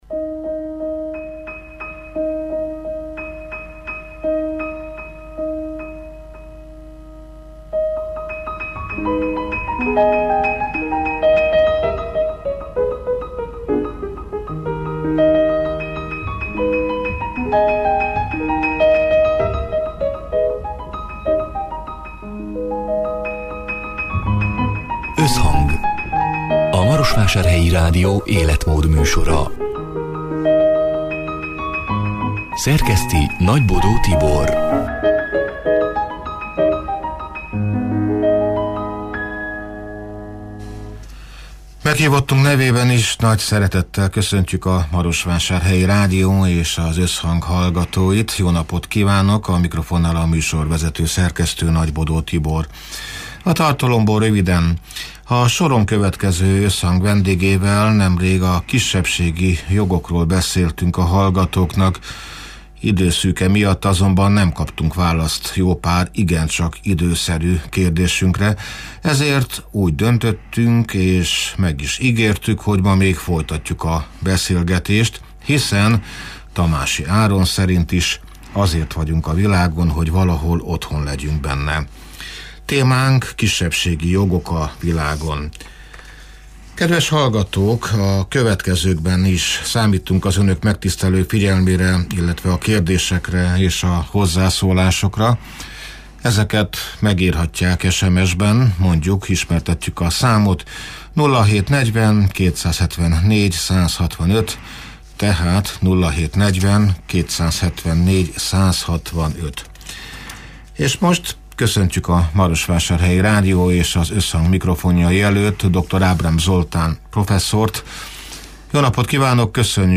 (elhangzott: 2024. december 11-én, szerdán délután hat órától élőben)